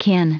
Prononciation du mot kin en anglais (fichier audio)
Prononciation du mot : kin